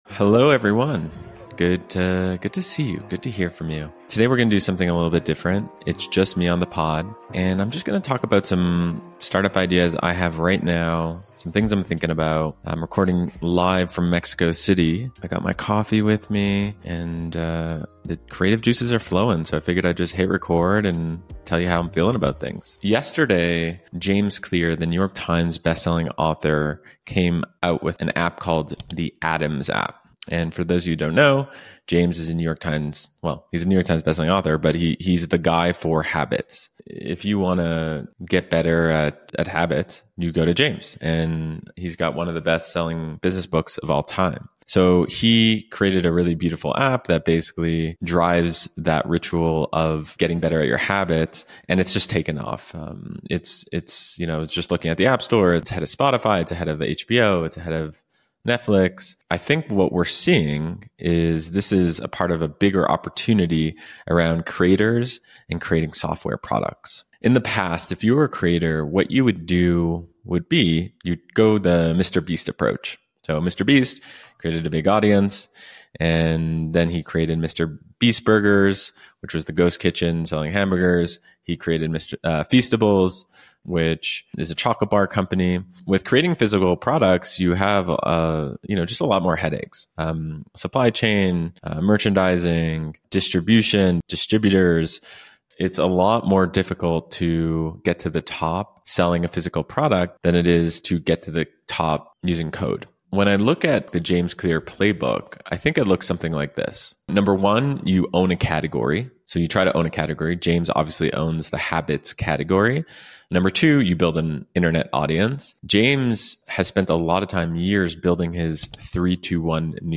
A special solo episode recorded from Mexico City, where I go through 3 million-dollar startup ideas I can’t stop thinking about: creators going into software, n…